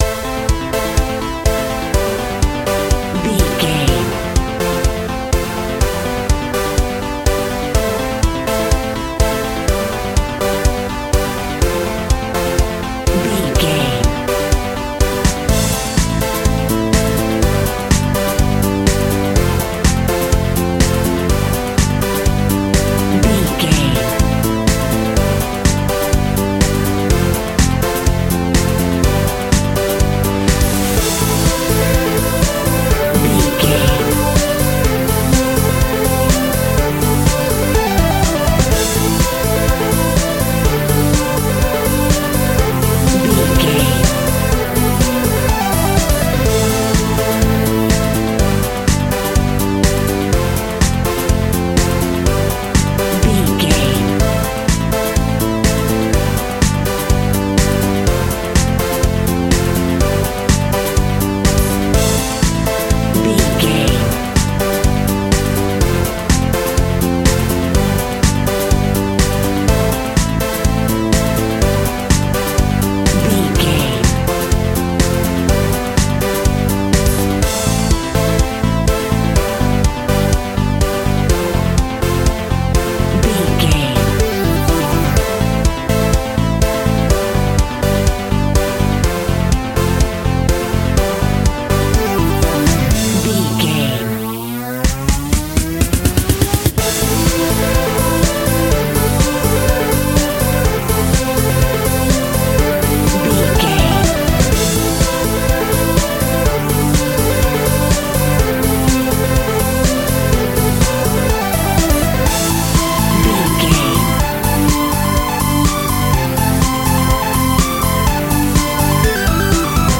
Electric Funky House Music.
Aeolian/Minor
groovy
uplifting
driving
energetic
drums
synthesiser
bass guitar
electronic
dance
synth leads
synth bass